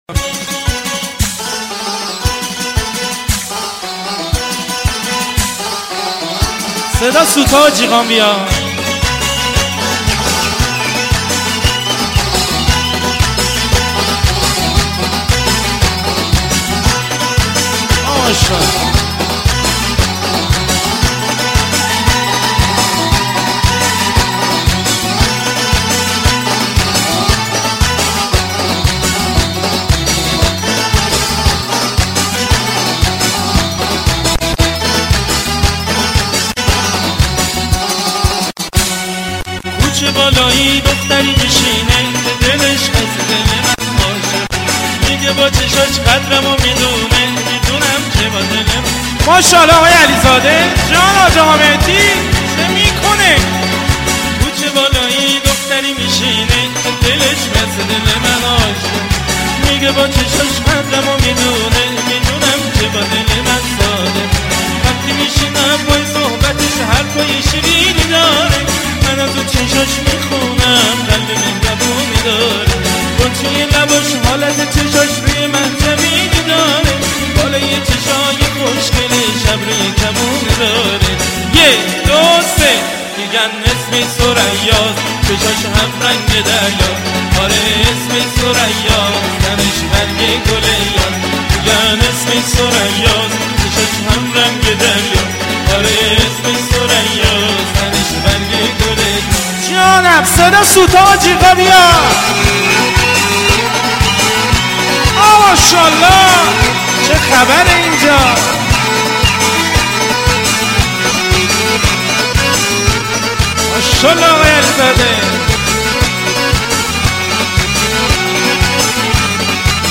آهنگ شاد ارکستی مخصوص عروسی